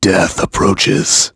Clause_ice-Vox_Skill5.wav